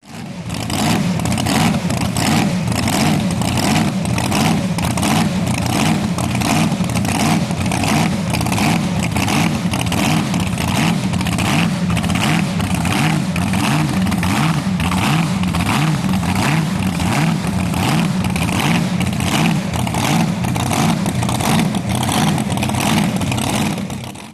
Click here to hear blower motor
idle.wav